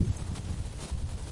THTR 237 现场录音项目第一周 " WInd, Trees, and Tags ( 已编辑)
描述：在UMBC校园中编辑的树木风的记录。